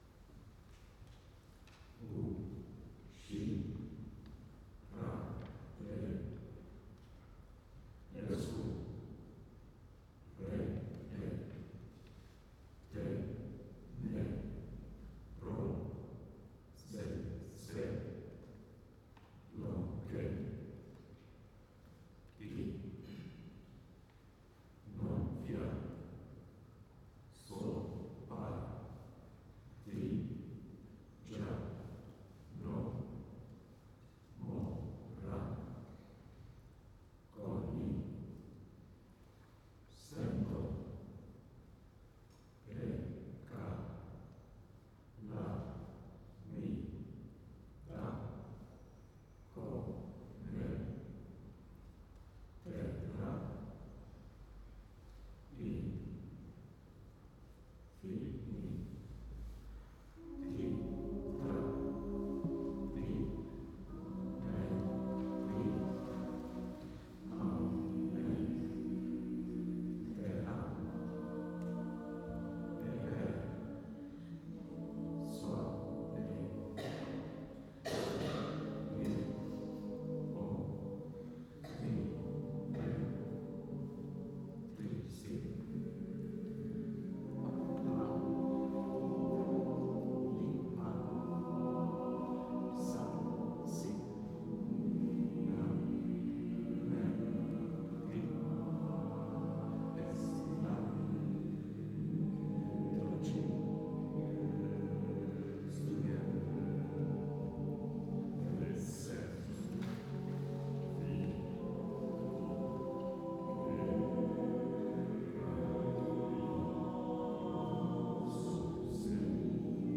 Sopranen
Tenoren
Bassen / baritons